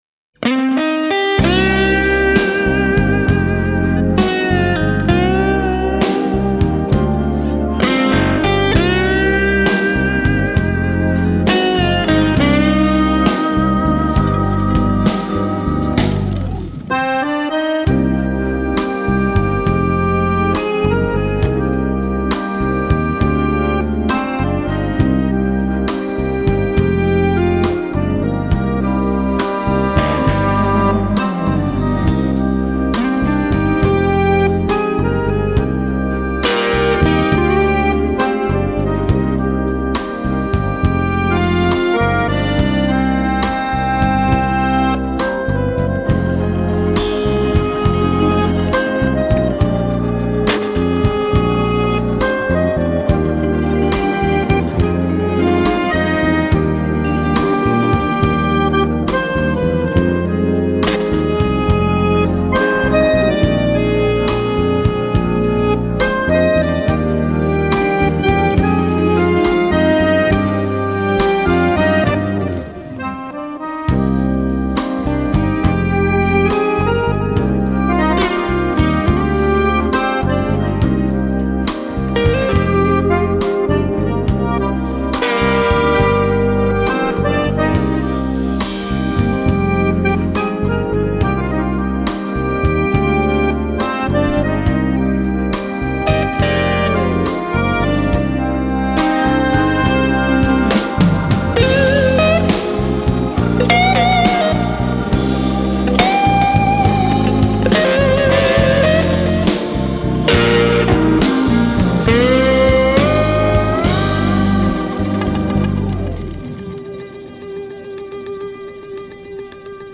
аккордеонистка из Франции